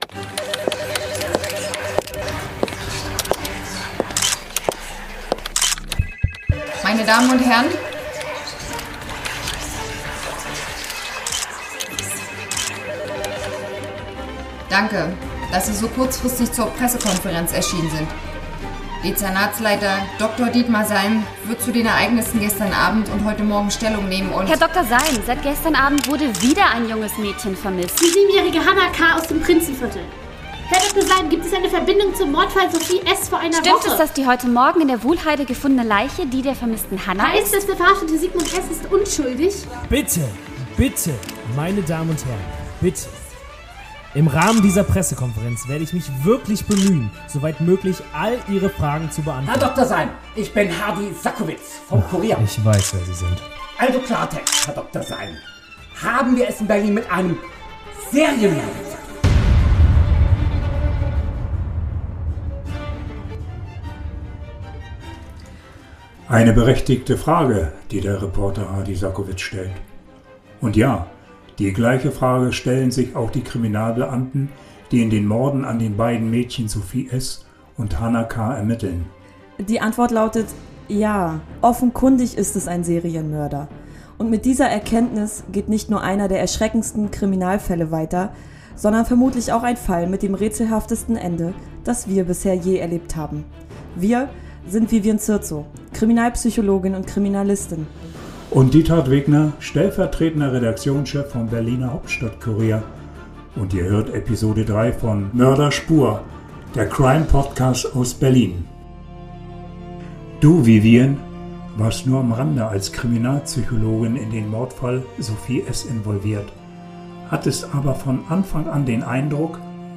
(als Kriminalpsychologin)
(als Frau u.a.)